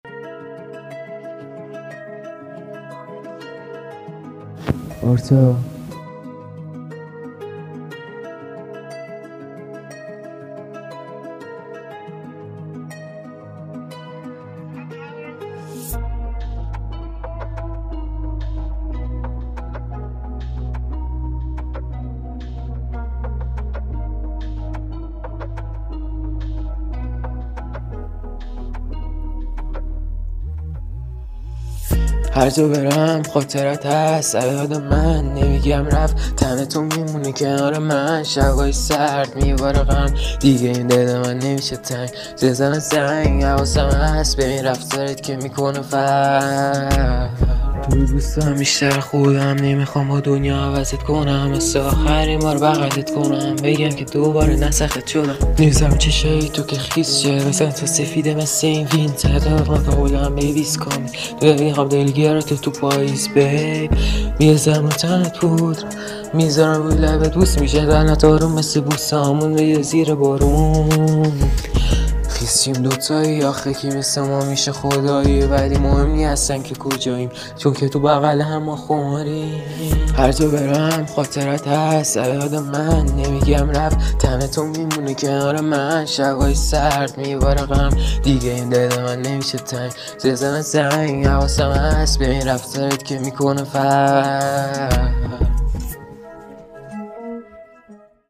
عاشقونه مود دپ غمگین آرندبی